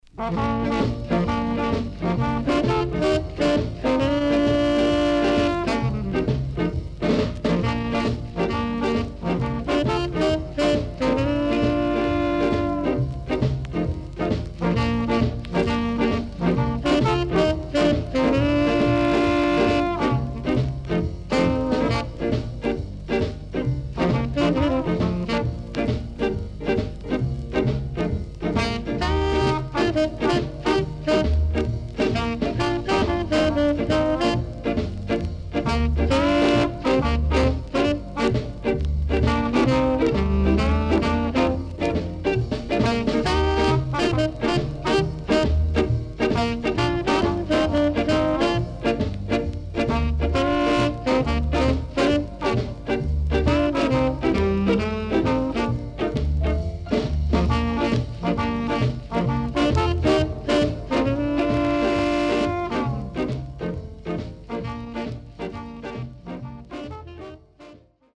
INST 60's
南国ムード溢れる曲から、ルードで男らしさが滲み出ている曲、更には信仰宗教を彷彿させるキラーな曲まで幅広く収録されている。
SIDE A:所々チリノイズがあり、少しプチノイズ入ります。
SIDE B:所々チリノイズがあり、少しプチノイズ入ります。